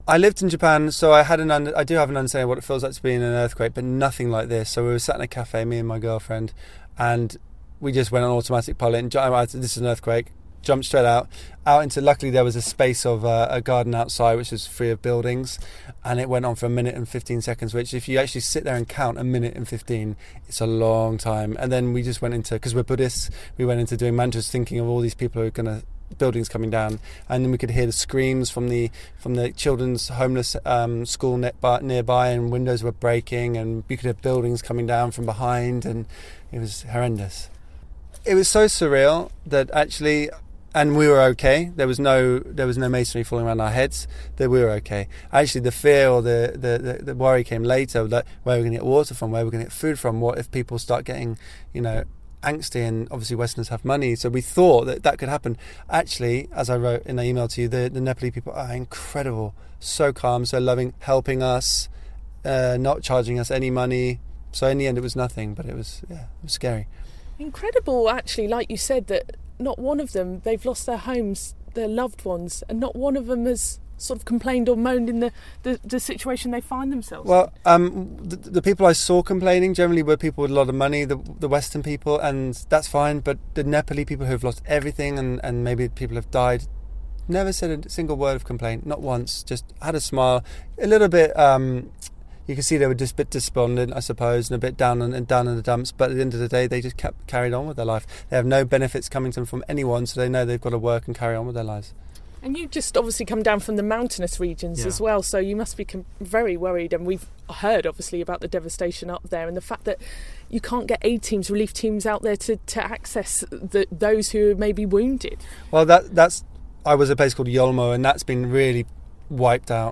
He has been speaking to Wave 105 about the devastation it has caused.